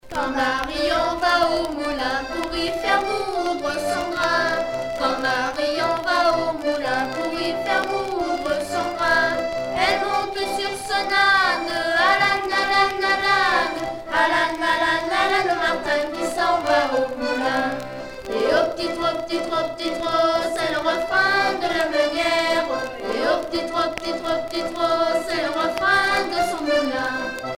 Chansons traditionnelles, vol. 1 par des enfants de France
Pièce musicale éditée